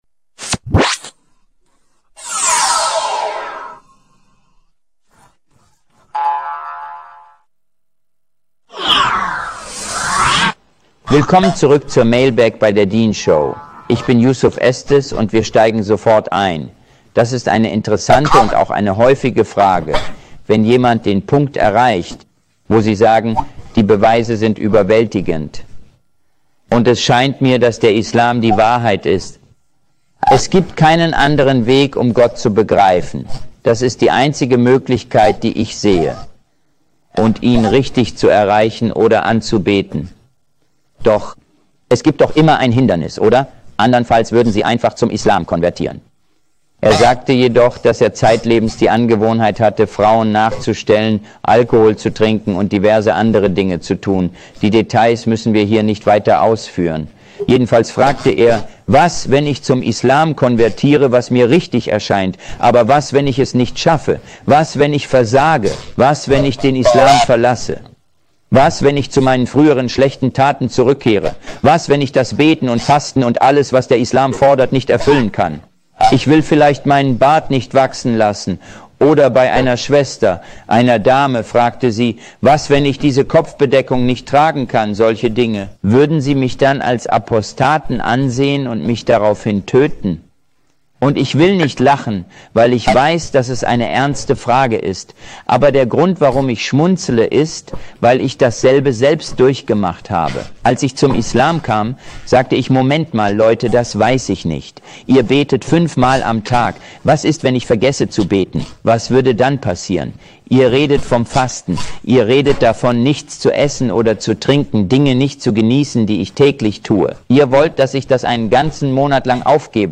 wie er diese Fragen in diesem Vortrag behandelt!